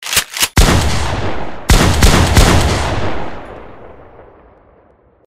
3shots.mp3